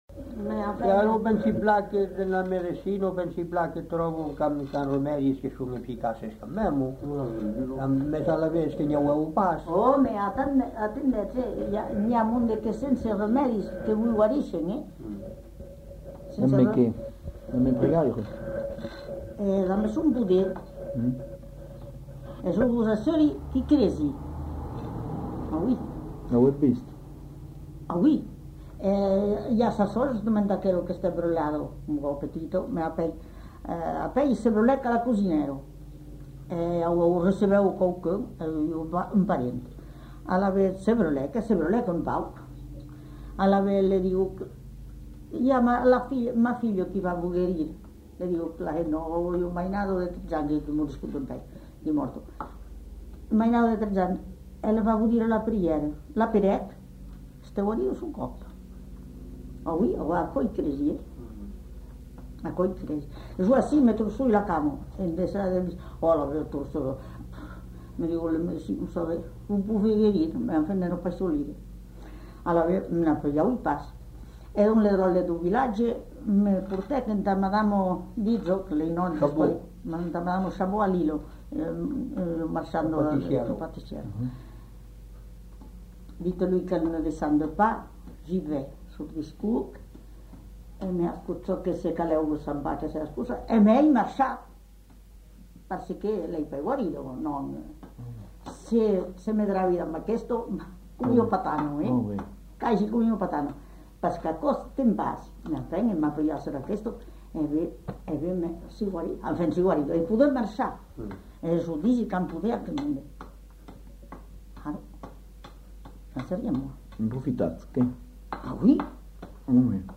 Lieu : Cazaux-Savès
Genre : témoignage thématique